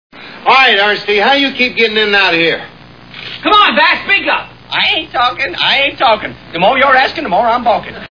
The Andy Griffith TV Show Sound Bites